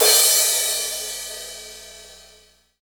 Index of /90_sSampleCDs/Sound & Vision - Gigapack I CD 1 (Roland)/CYM_K-CRASH st/CYM_K-Crash st 2
CYM CRA3509R.wav